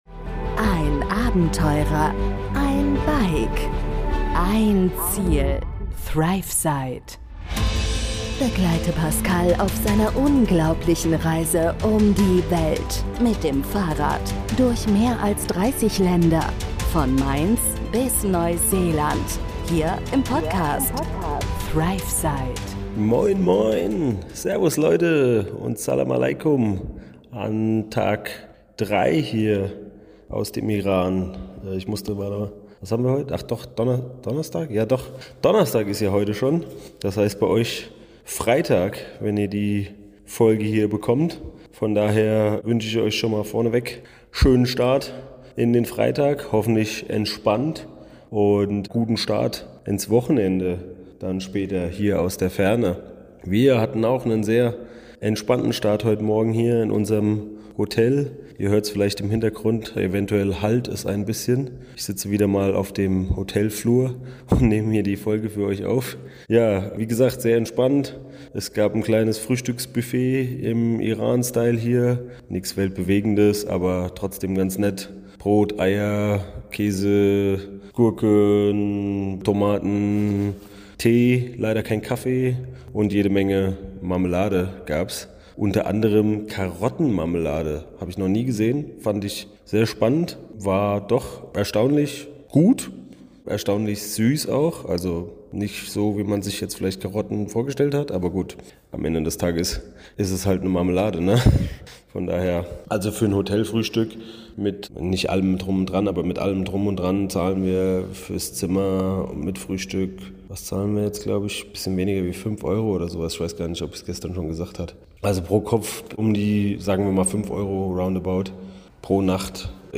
Hey everyone! In this episode, we take you along on our adventure in Iran, straight from a hotel hallway! Experience with us a breakfast buffet Iranian style, featuring carrot jam and everything that comes with it – sadly, no coffee!
🩳 Find out how we navigate the city with offline maps in search of some cool air and coffee, eventually landing in a hidden café full of smoky vibes and friendly locals. Listen to how we not only get free coffee but also find ourselves in a spontaneous Rammstein sing-along session!